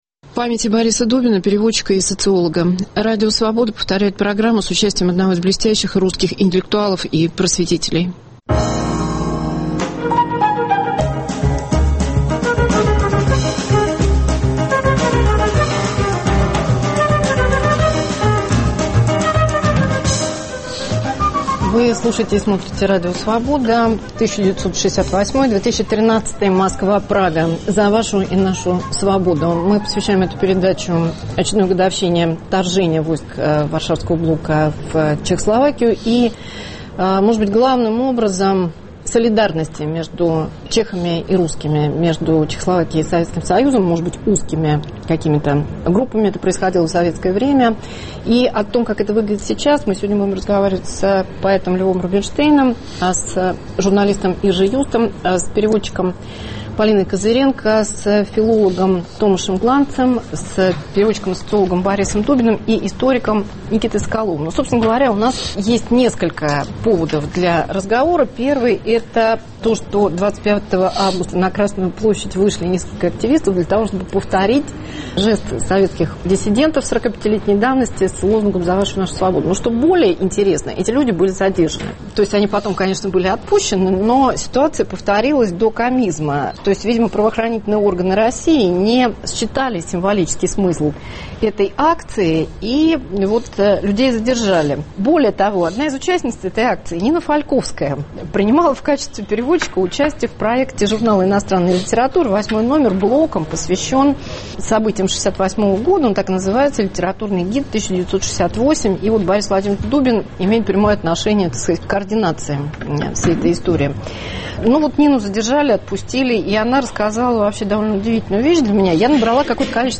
Радио Свобода повторяет программу с участием одного из блестящих русских интеллектуалов и просветителей. Невостребованная солидарность – к годовщине советского вторжения в Прагу.